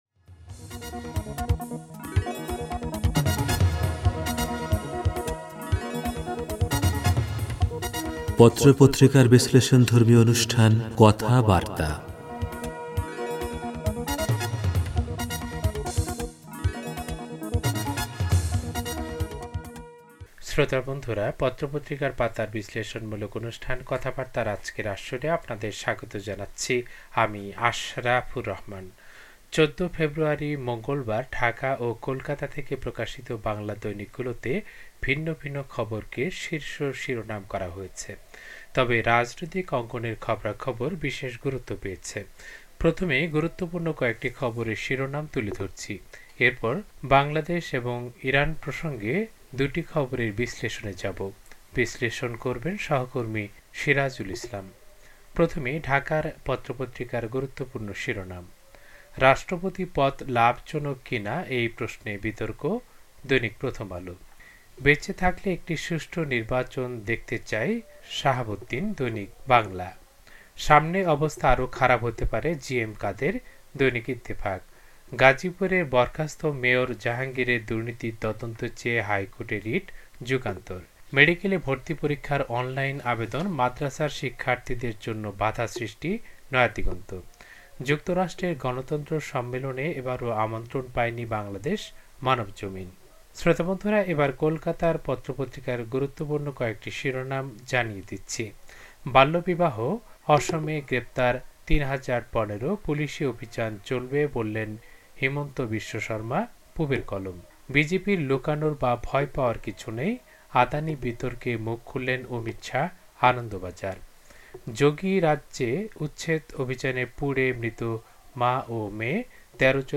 পত্রপত্রিকার পাতার অনুষ্ঠান কথাবার্তা